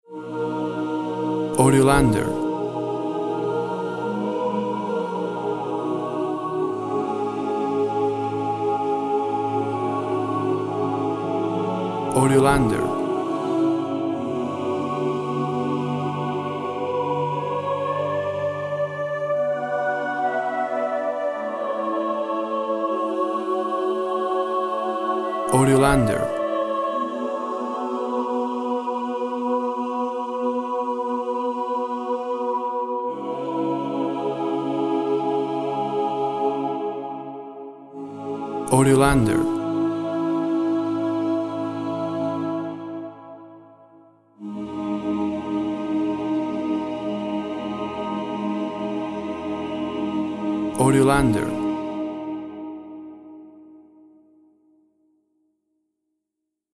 Choir sings with reverb for a dream-like quality.
Looped Audio No
key Bbmi /Abmi
Tempo (BPM) 64